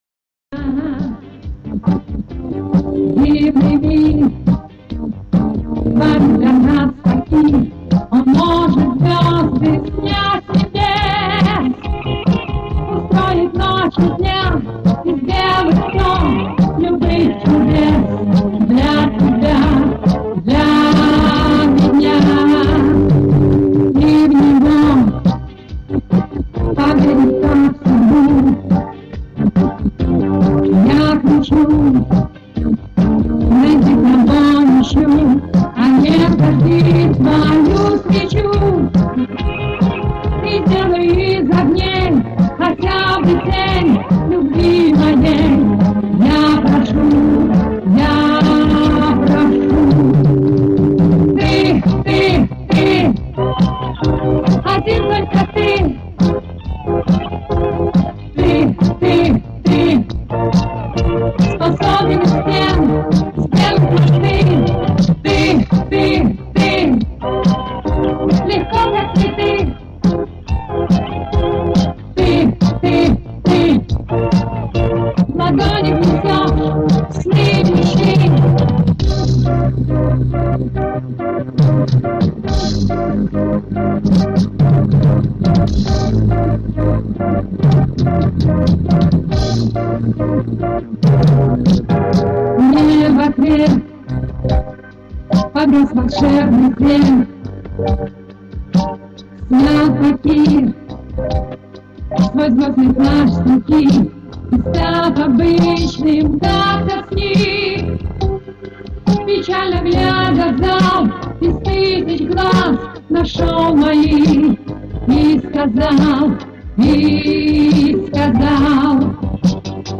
Вот к примеру моя оцифровка одной песни, до сих пор ищется.
За качество извините, уж как сохранилось.